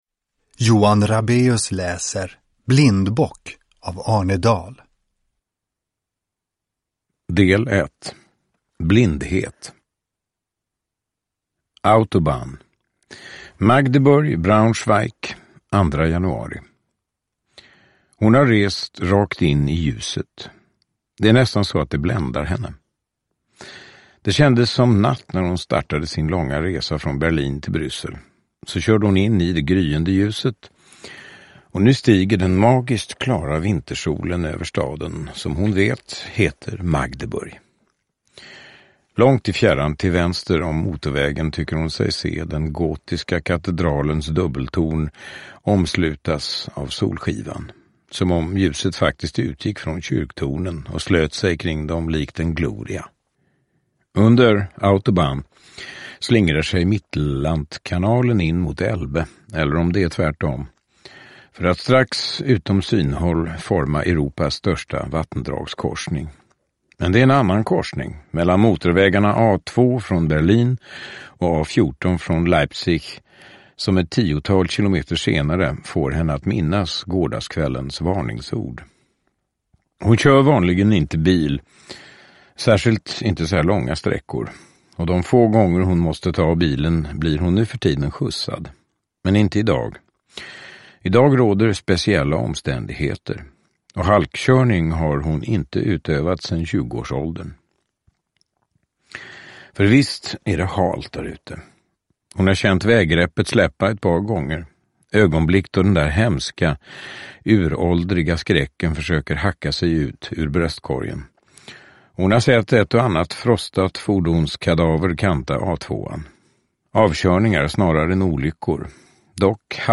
Nedladdningsbar Ljudbok
Berättare
Johan Rabaeus